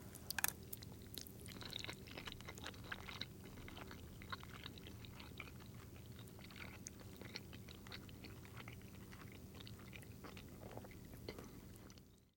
На этой странице собраны разнообразные звуки, связанные с макаронами: от шуршания сухих спагетти до бульканья кипящей воды.
Звук пережёвывания макарон человеком